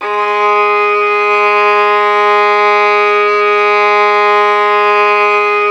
Index of /90_sSampleCDs/Roland - String Master Series/STR_Violin 4 nv/STR_Vln4 % + dyn